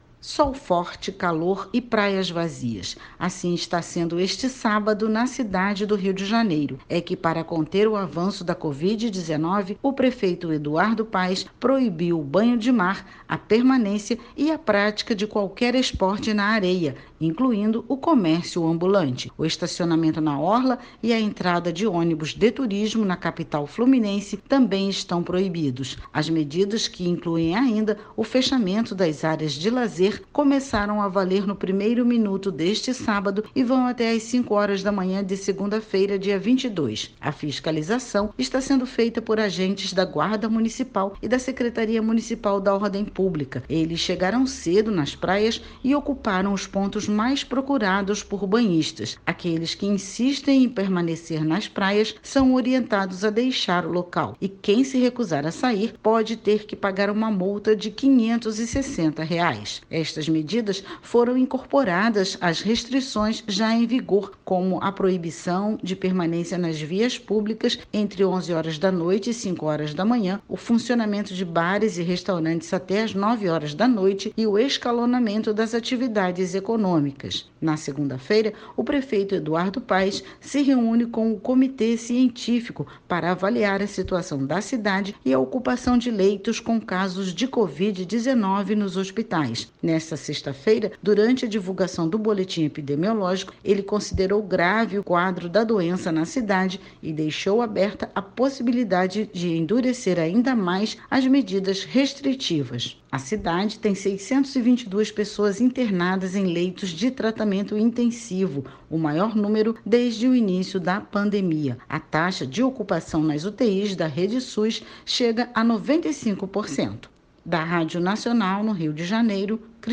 Balanço das fiscalizações - Repórter